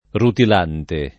rutilante [ rutil # nte ] agg.